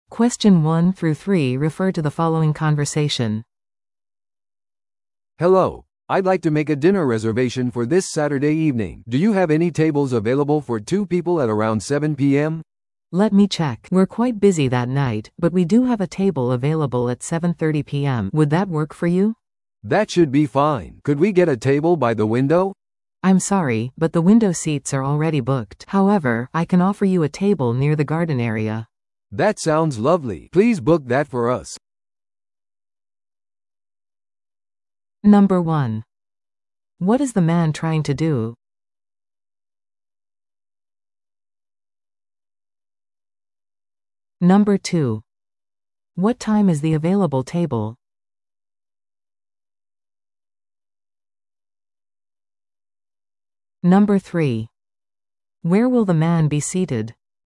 PART3は二人以上の英語会話が流れ、それを聞き取り問題用紙に書かれている設問に回答する形式のリスニング問題です。